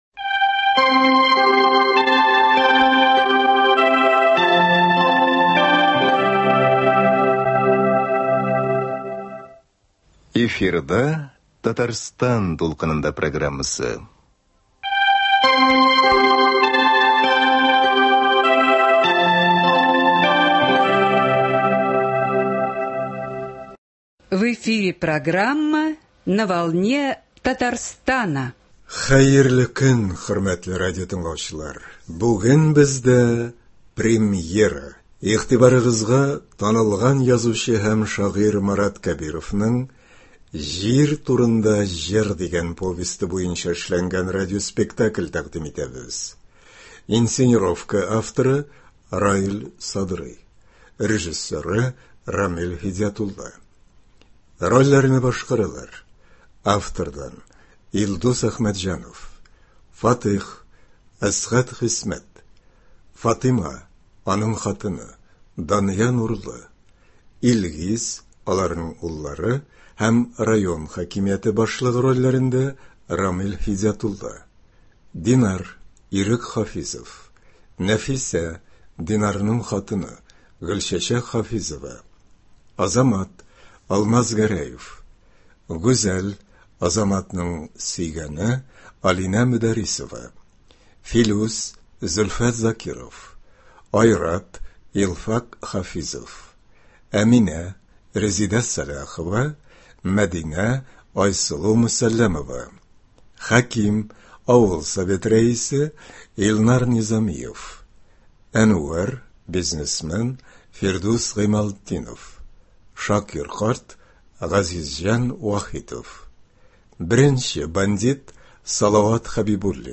“Җир турында җыр” Радиоспектакль премьерасы (12.11.23)